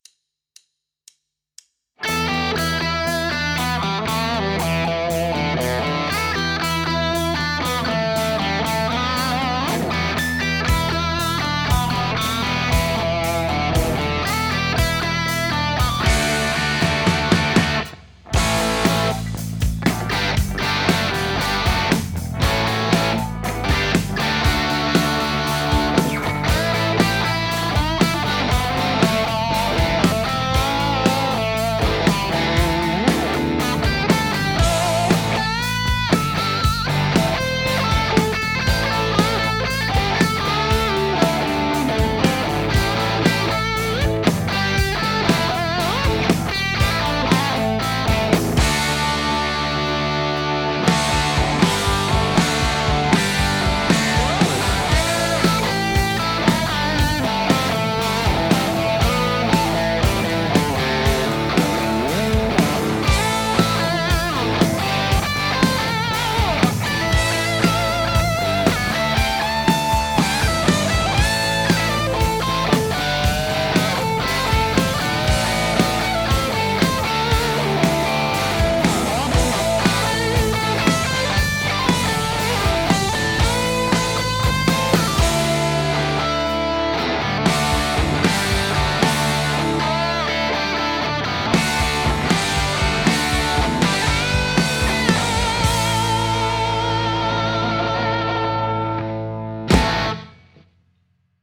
Gutes Einstiegsmotiv, sehr tough und straight (wie ACDC halt sein muss)...
Double-Bends gefallen mir gut (ich kann die einfach nicht :( )
Sehr gutes und passendes solo !
ACDC-Jam.mp3